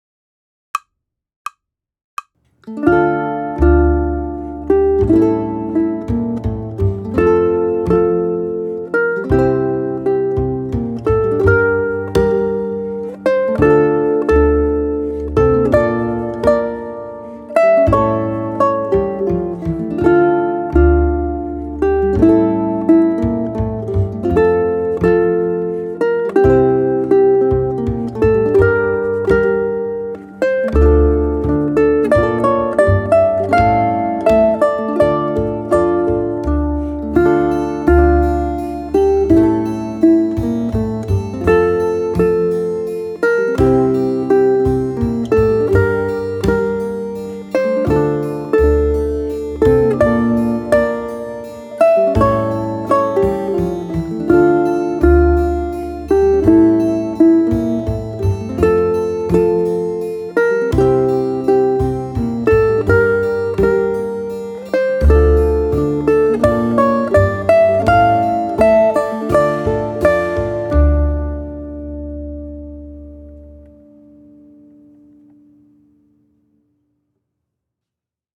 George Handel's stately Baroque dance, Sarabande, is an optional piece appropriate for Project 3 or 4.
The sarabande is a slow, stately Spanish dance in triple time, peaking in popularity during the seventeenth and eighteenth centuries in Europe.
I recorded Sarabande using arpeggiated chords in a P-a-m-i-m-a pattern.
The metric feel briefly changes to duple at measures 14 and 15, requiring a strum every two beats or a switch to P-i-m-a. The triple meter feel resumes at measure 16.
ʻukulele